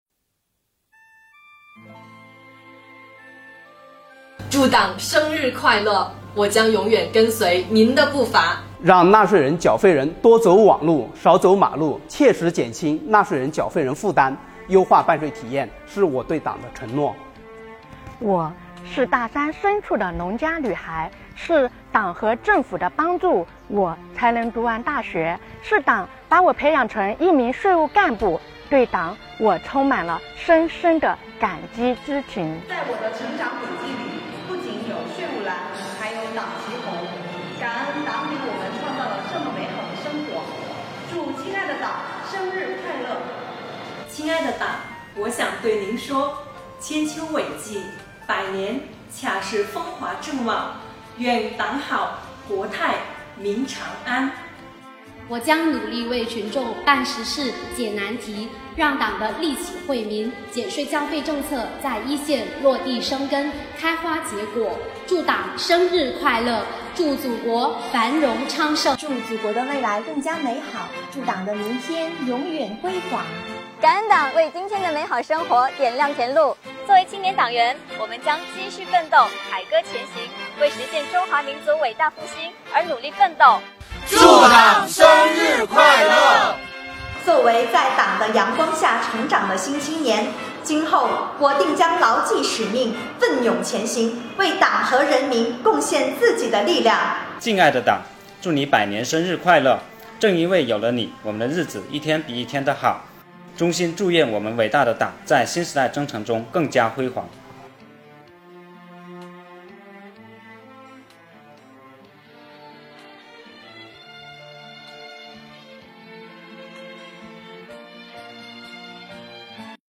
广西税务的党员同志对党的感恩和祝福！